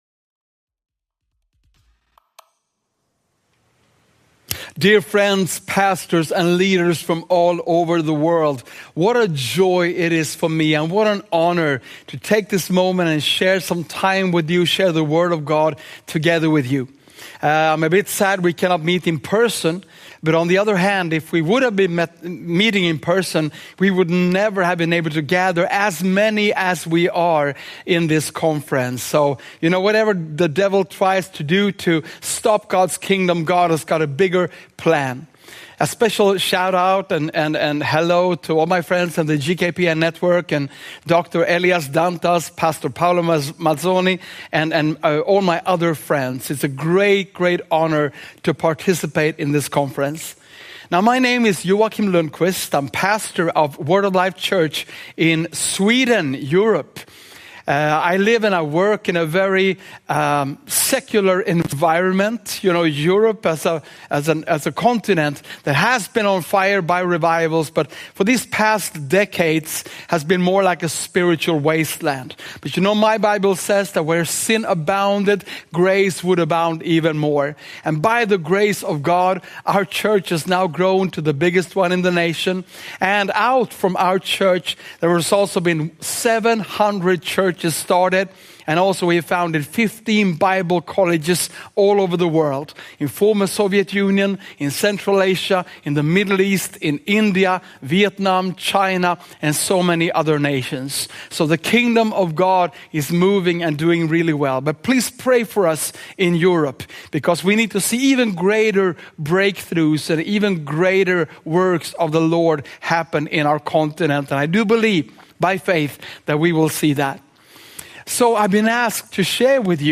Mensagem